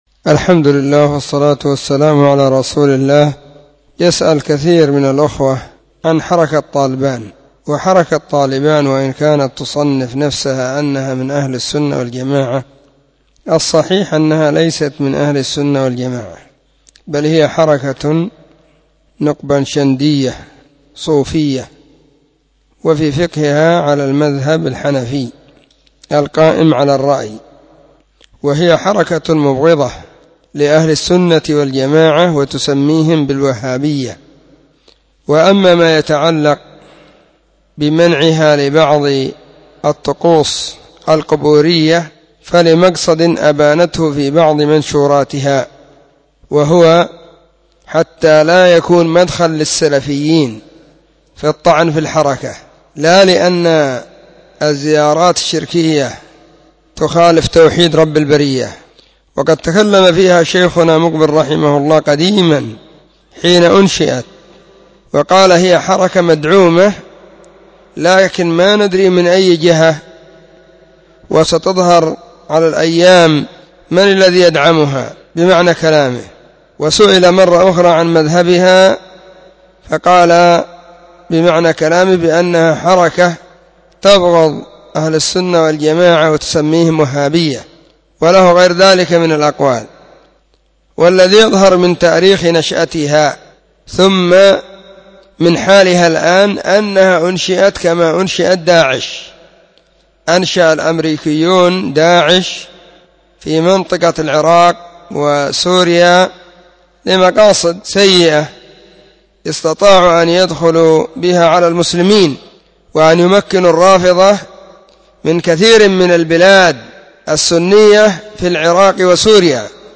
📢 مسجد الصحابة – بالغيضة – المهرة، اليمن حرسها الله.
🔸🔹 سلسلة الفتاوى الصوتية المفردة 🔸🔹